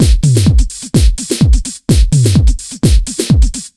127BEAT8 5-L.wav